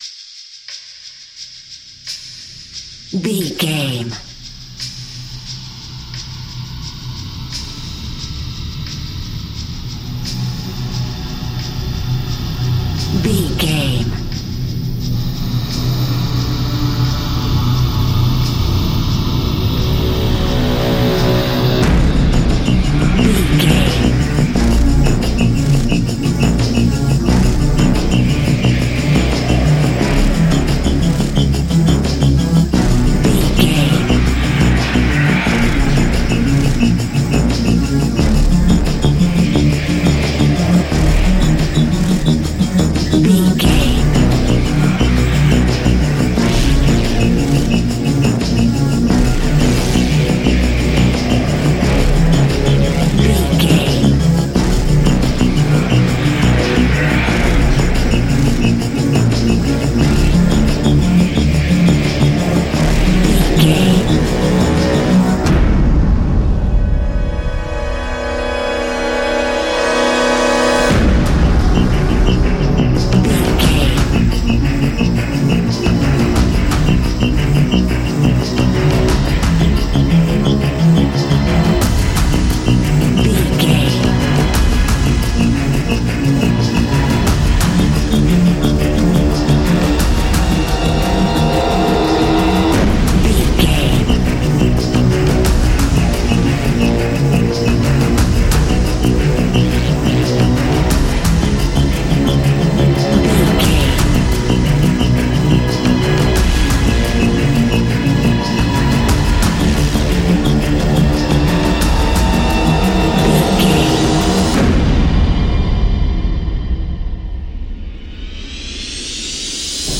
Fast paced
In-crescendo
Ionian/Major
B♭
industrial
dark ambient
EBM
drone
synths
Krautrock